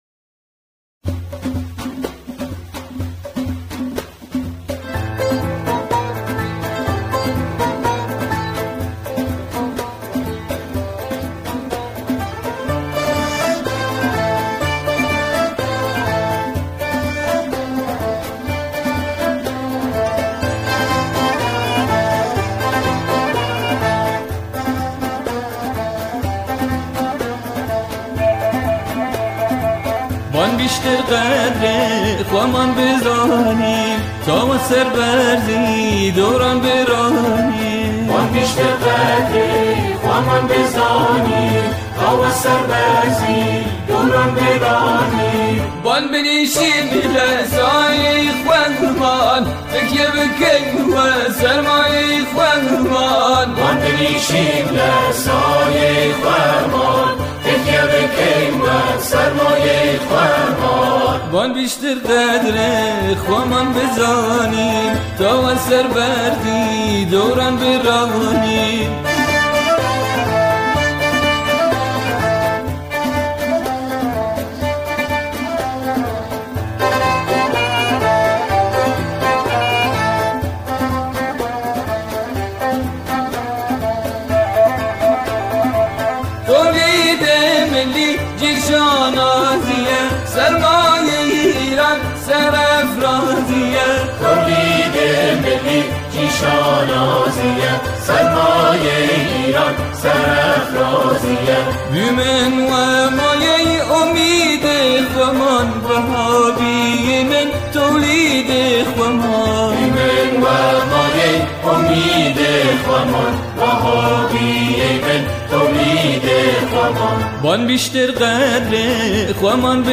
اجرای گروه همخوان در این قطعه، برجسته و قابل توجه است.